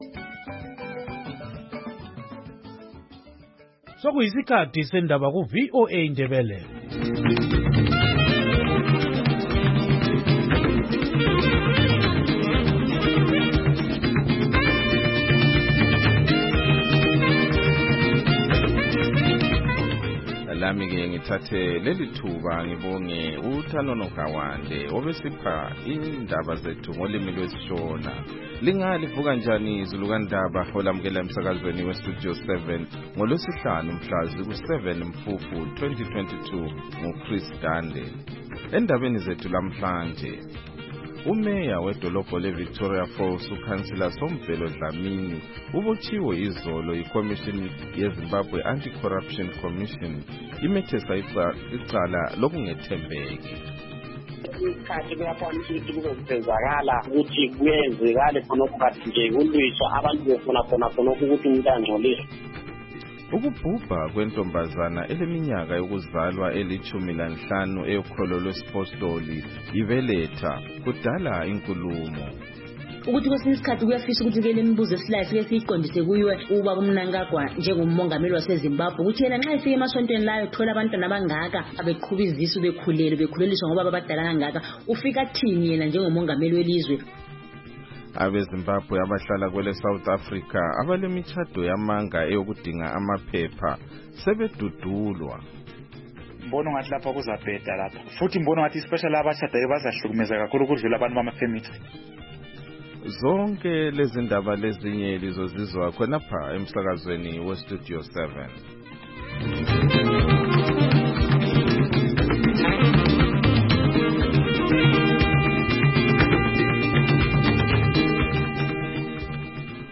Silohlelo lwezindaba esilethulela lona kusukela ngoMvulo kusiyafika ngoLwesine emsakazweni weStudio 7 ngo6:30am kusiyafika ihola lesikhombisa - 7:00am.